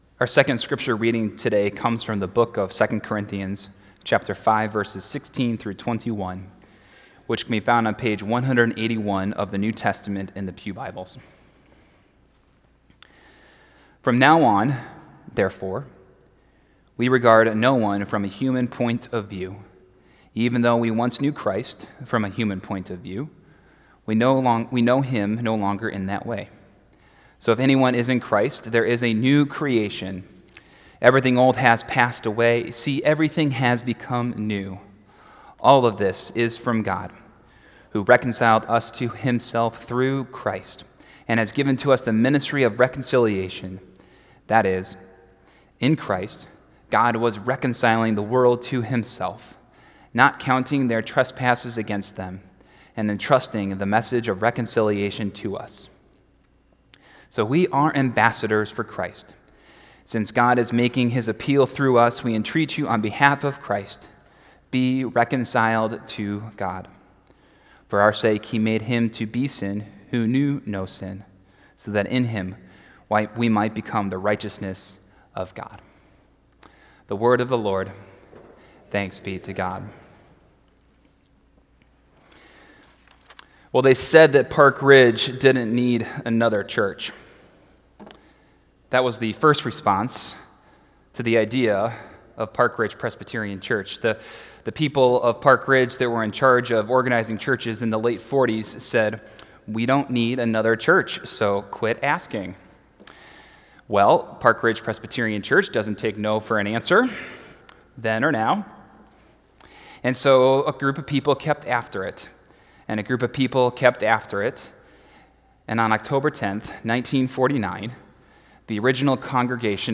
70th Anniversary Celebration Sunday